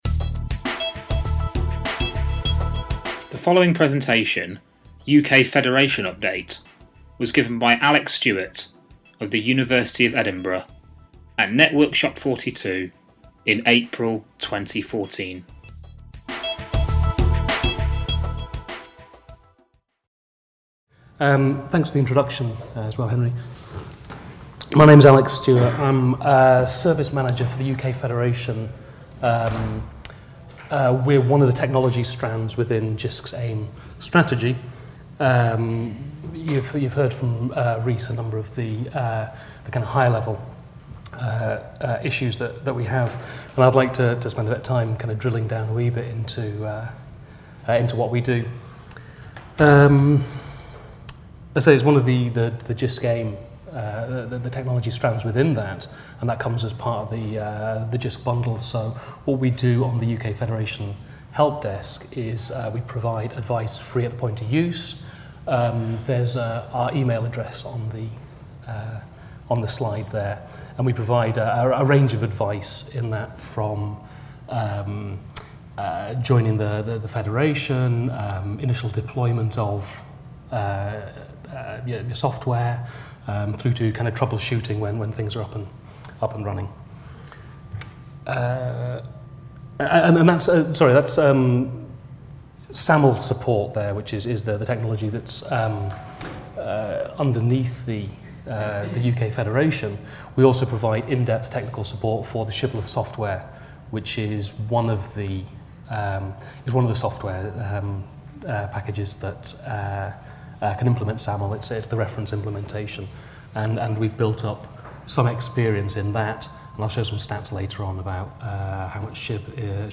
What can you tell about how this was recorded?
Networkshop 42